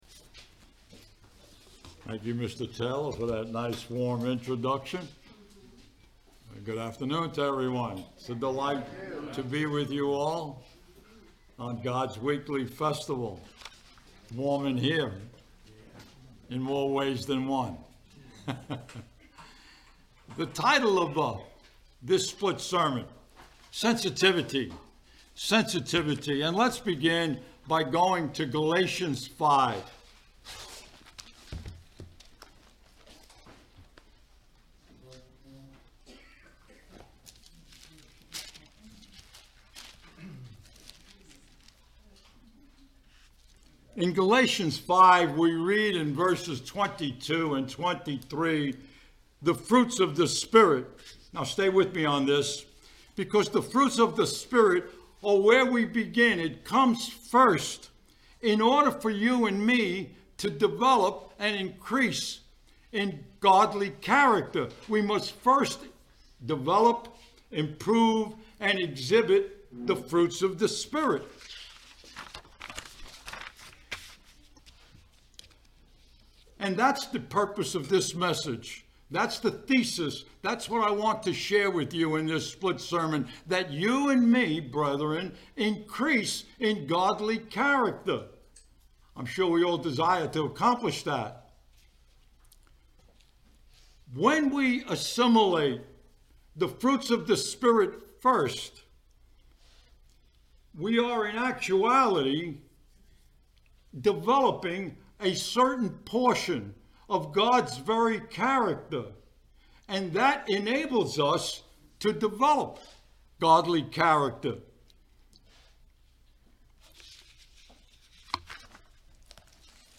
Given in Ocala, FL